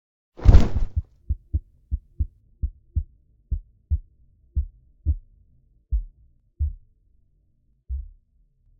Damage1.ogg